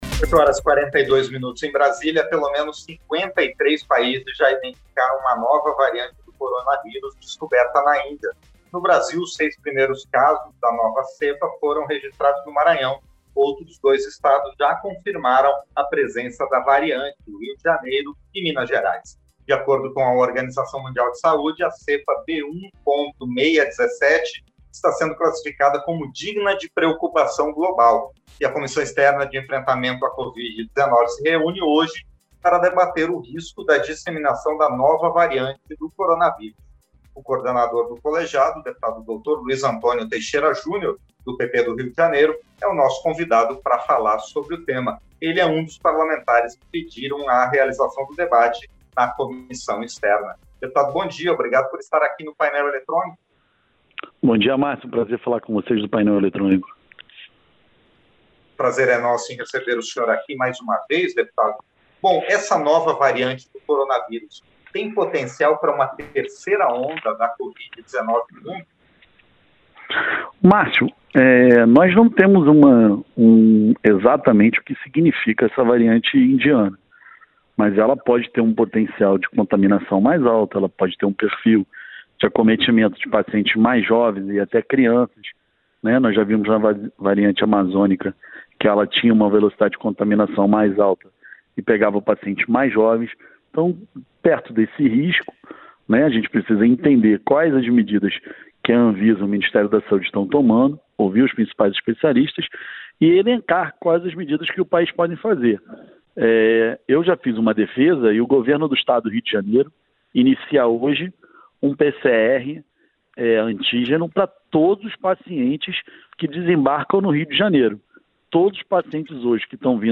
• Entrevista - Dep. Dr. Luiz Antônio Teixeira Jr. (PP-RJ)
Programa ao vivo com reportagens, entrevistas sobre temas relacionados à Câmara dos Deputados, e o que vai ser destaque durante a semana.